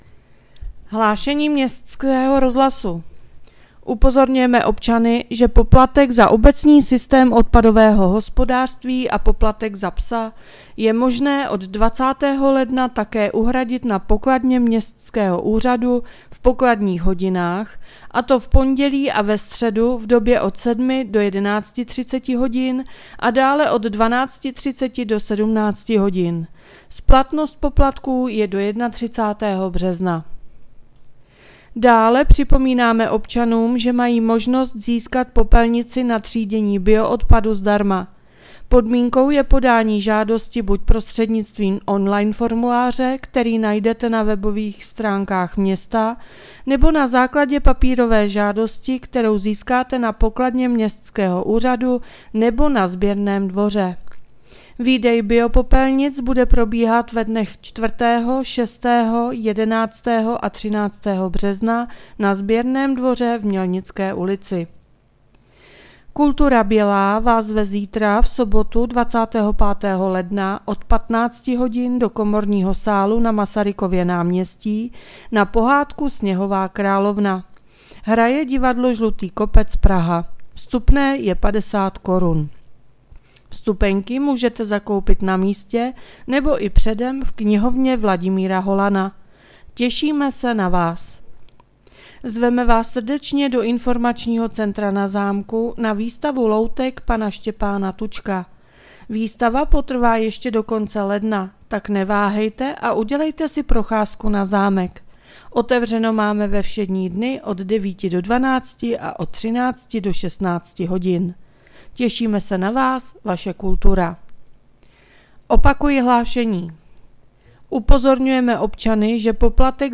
Hlášení městského rozhlasu 24.1.2025